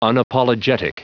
Prononciation du mot unapologetic en anglais (fichier audio)
Prononciation du mot : unapologetic